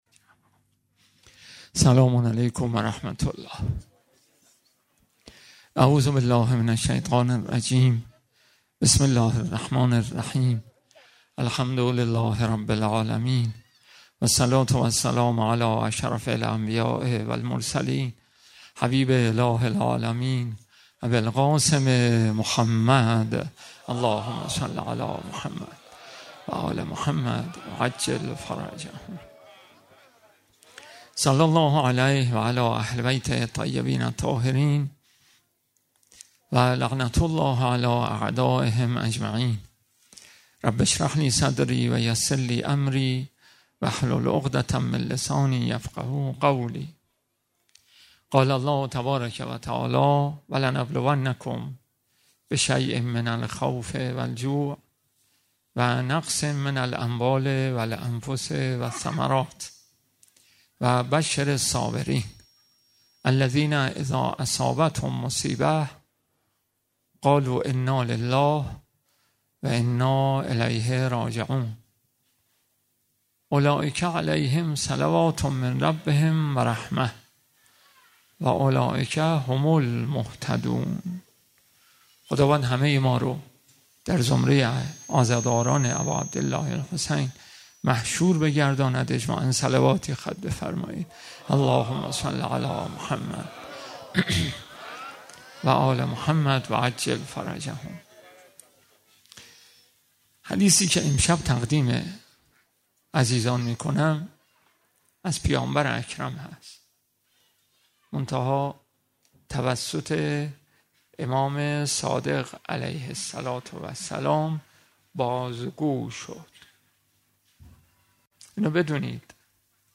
گلزار شهدای گمنام شهرک شهید محلاتی